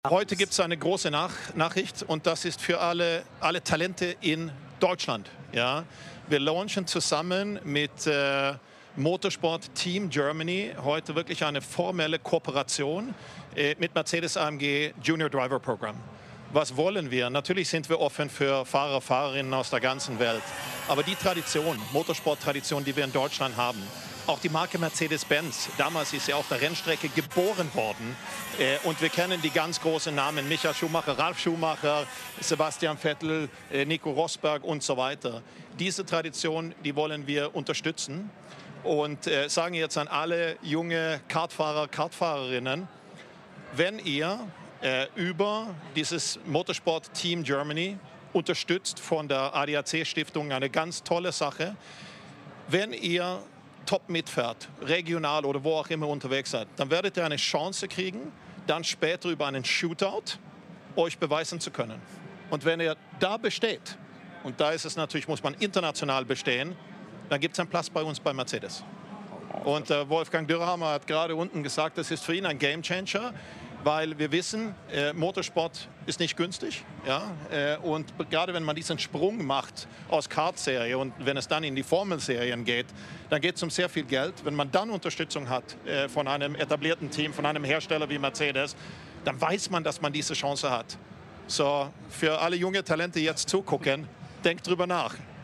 Audio-Statement von Ola Källenius (MP3) zum Download